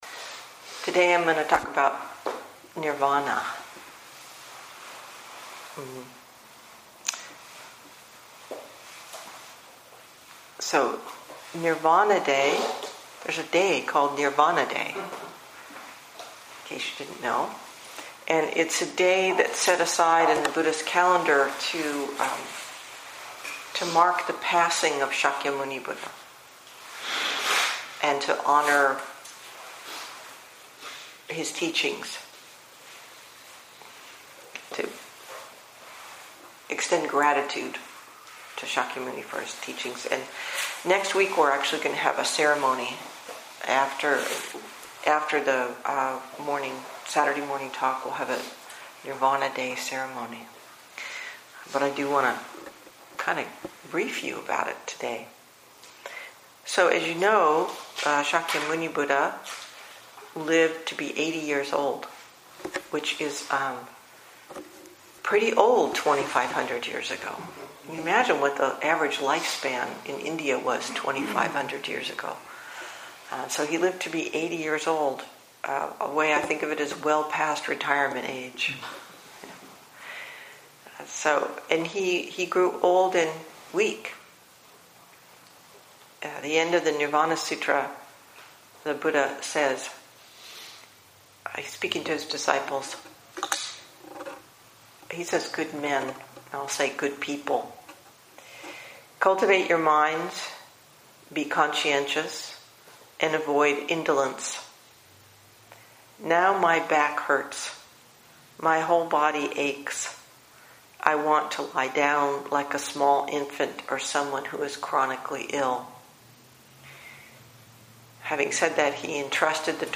Parinirvana Day Teaching